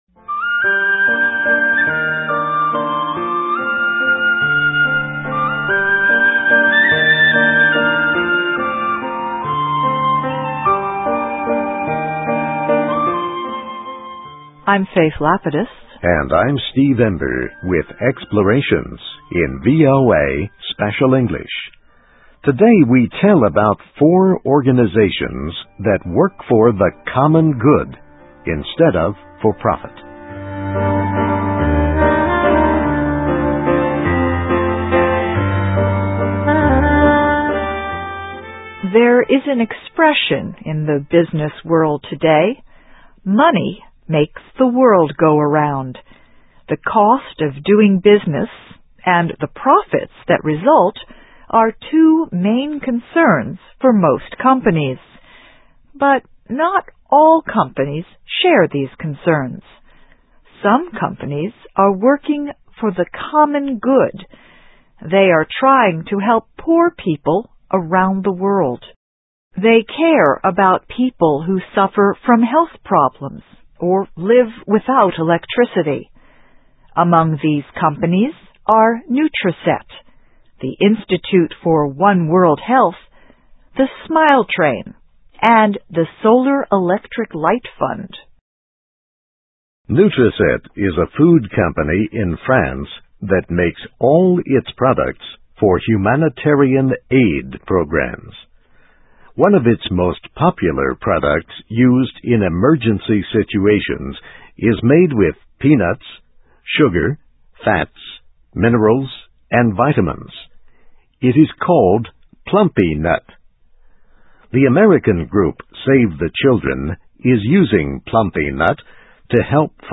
ESL, EFL, English Listening Practice, Reading Practice